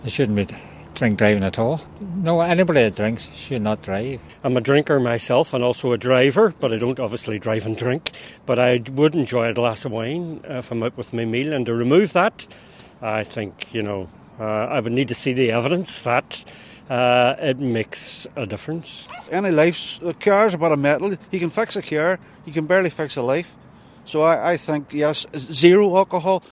These people in Derry have been asked if they think there should be a change: